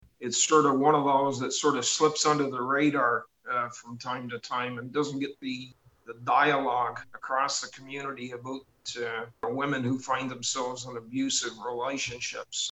At its recent meeting, the committee recommended that Hastings County Council approve $32,000 annually for the next two years, towards a rent-subsidy program for the home.
Belleville Councillor Bill Sandision called it a “great institution” to have in the community.